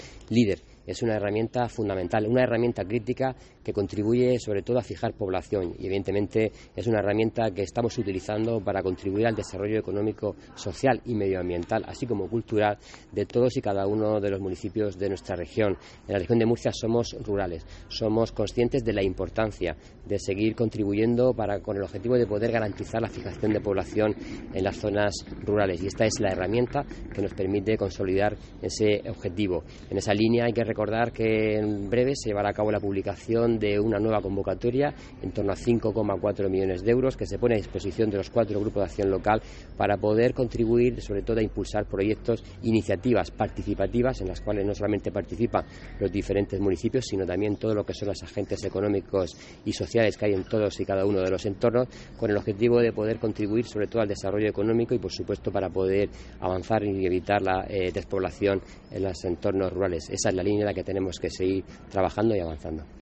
Antonio Luengo, consejero de Agua, Agricultura, Ganadería, Pesca y medio Ambiente
Luengo, que ha hecho estas declaraciones en el acto inaugural de la Asamblea de la Red Española de Desarrollo Rural, que se celebra en el Balneario de Archena, ha indicado también que estos retos "pueden afrontarse gracias a las ayudas que la Unión Europea pone a nuestra disposición a través de los diferentes Programas de Desarrollo Rural, o del Plan Estratégico de la PAC para el nuevo período, y que son gestionadas y cofinanciadas por la Comunidad Autónoma".